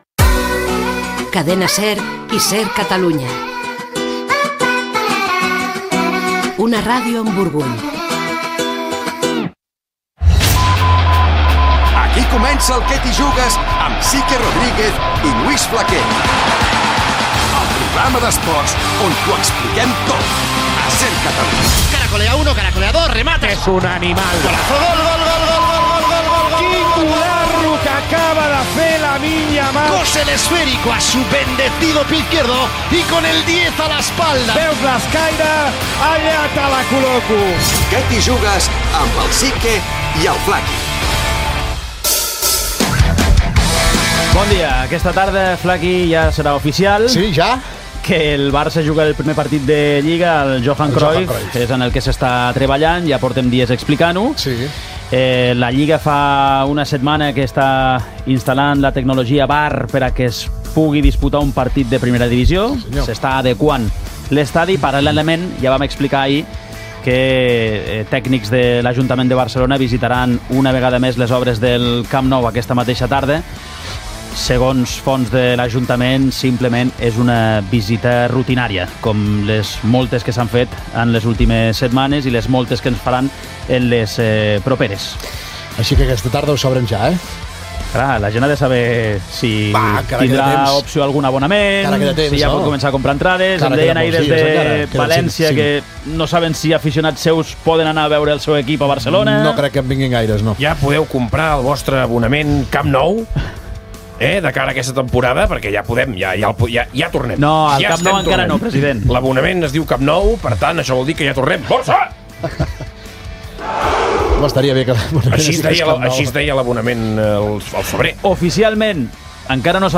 d7c70bf04b5ff688d79a25175f661e0e08468f57.mp3 Títol SER Catalunya Emissora SER Catalunya Cadena SER Titularitat Privada estatal Nom programa Què t'hi jugues! Descripció Indicatiu de SER Catalunya, careta, el partit de la lliga masculina entre el Futbol Club Barcelona-València es jugarà al camp Johan Cruyff, presentació de l'estrena del programa de SER Catalunya Què t'hi jugues Barça! (amb les imitacions de Pau Cubarsí, Joan Lporta, Vinicius, Hansi Flick, etc.), indicatiu cantat del programa.